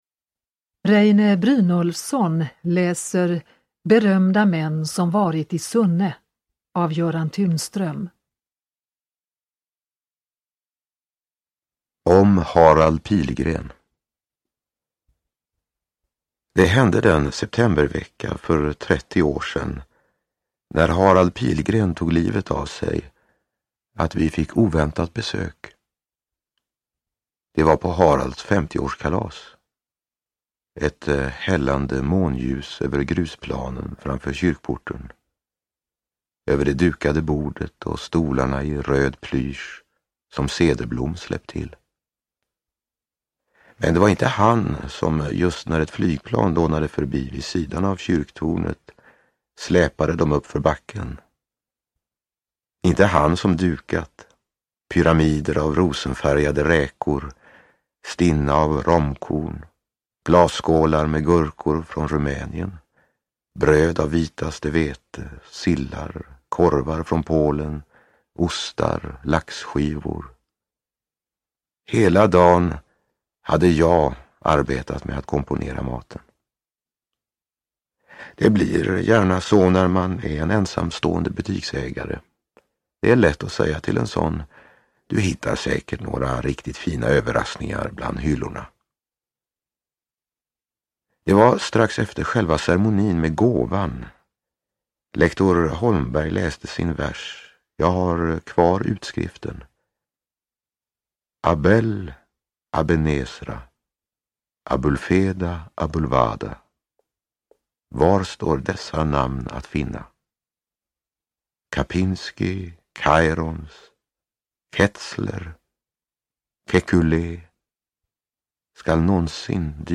Uppläsare: Reine Brynolfsson
Ljudbok
Reine Brynolfsson läser i en inspelning från 1998.